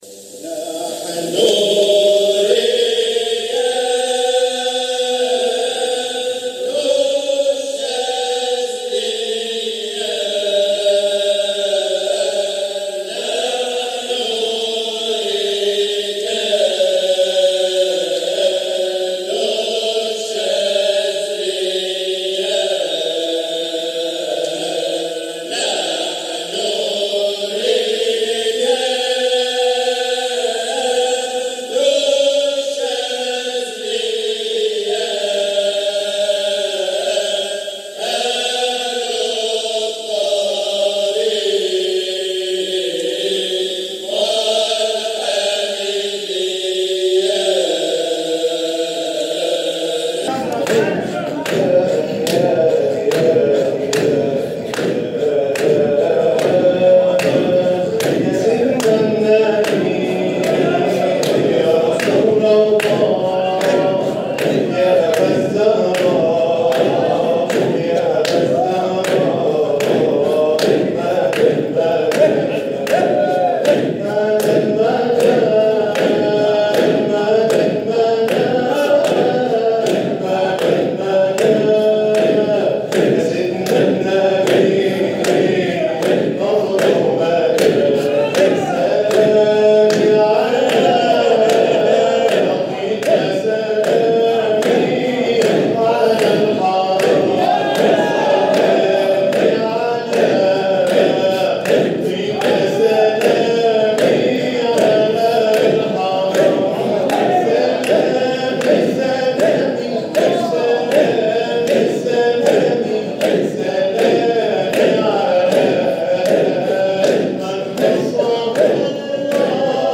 جزء من حلقة ذكر